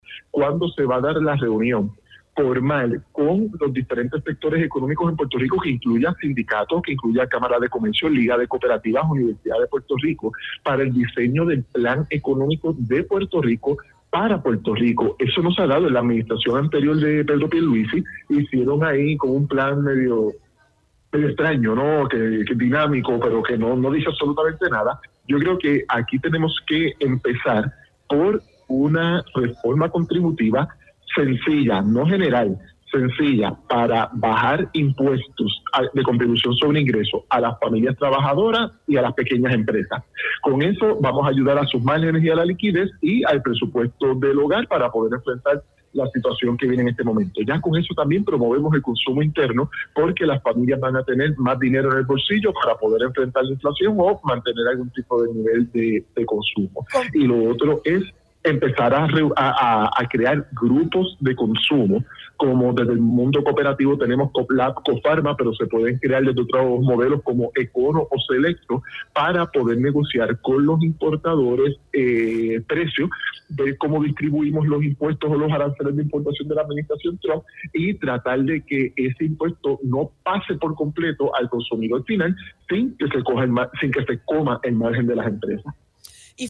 en entrevista para Dígame la Verdad